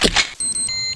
c4_plant2.wav